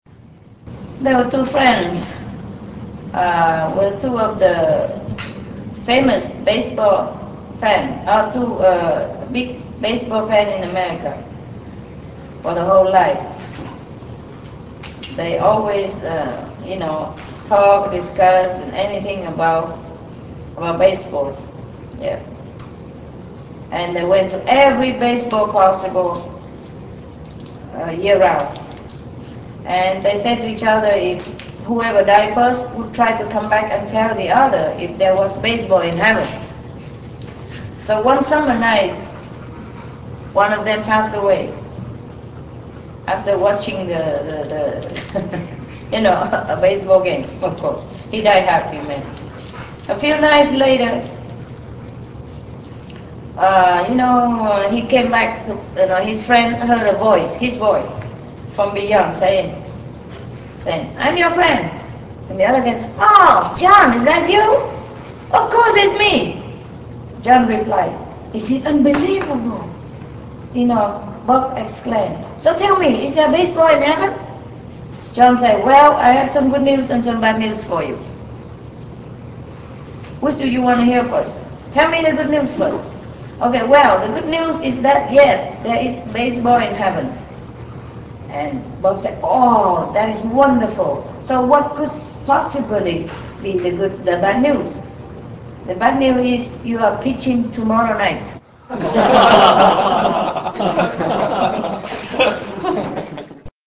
ปราศรัยโดยอนุตราจารย์ชิงไห่ ฟลอริด้า สหรัฐอเมริกา